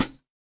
Roland.Juno.D _ Limited Edition _ Brush Kit _ Hh.wav